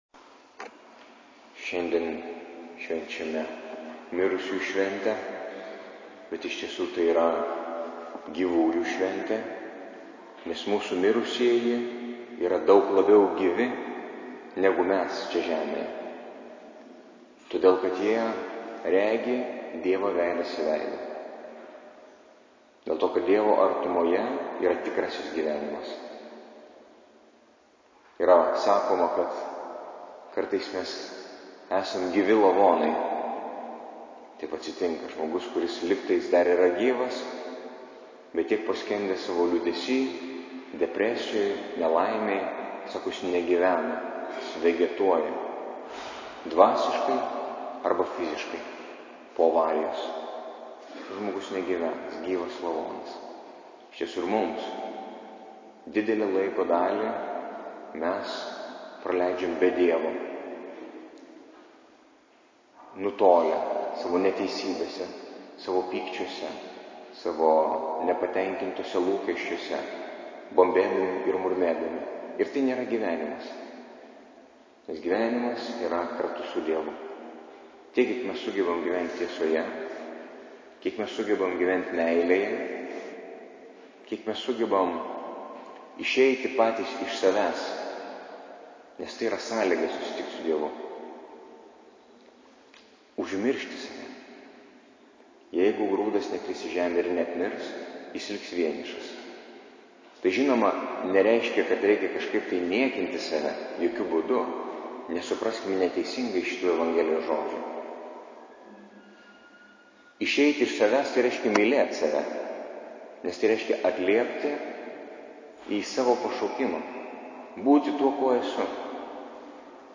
Audio pamokslas Nr1: